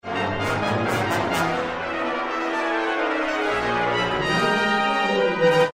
< Festive music for the 50th Anniversary of AUA > for Brass Ensemble
1st Trumpet in Bb
1st Horn in F
1st Trombone
Bass Trombone
Tuba